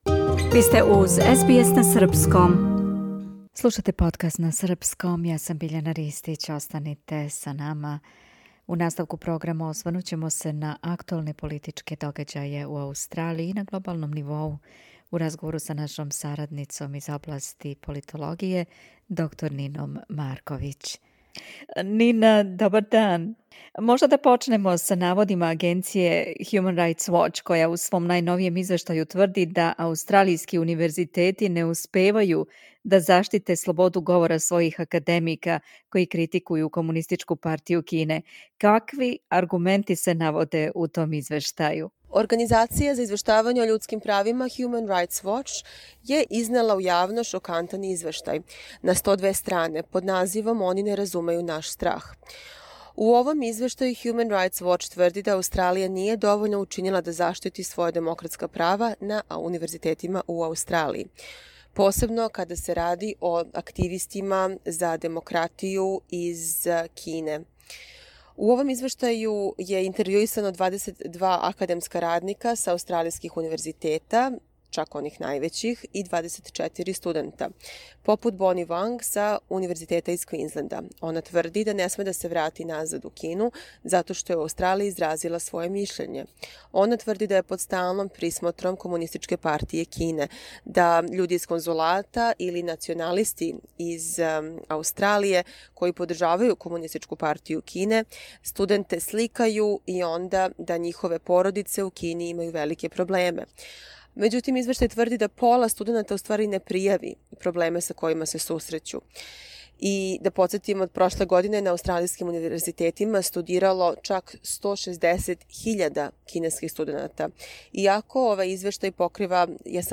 Осврт на актуелне политичке догађаје у Аустралији и на глобалном нивоу, у разговору с нашом сарадницом